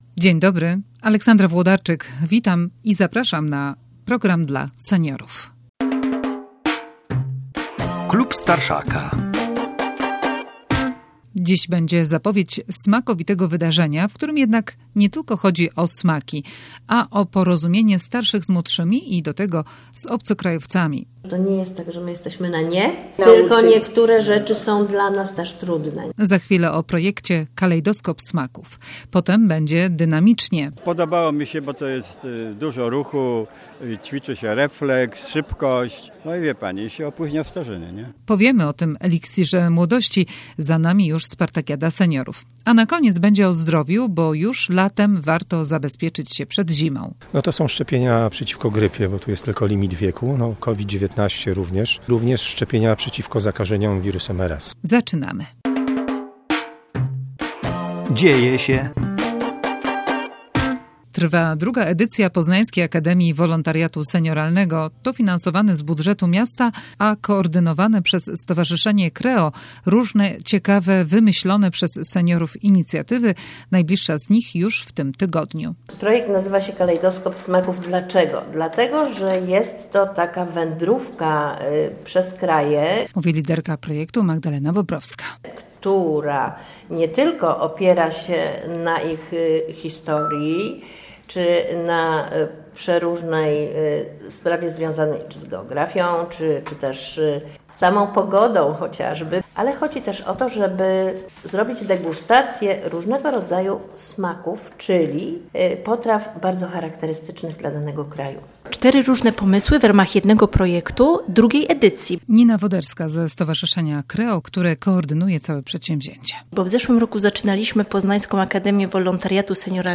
Za nami 18-sta spartakiada seniorów - seniorzy opowiedzą dlaczego biorą w niej udział i co im daje aktywność fizyczna i rywalizacja. Na koniec lekarze będą zachęcać do szczepień profilaktycznych przed zimą, bo w aptekach sa juz darmowe dla osób 65+ szczepionki przeciwko grypie.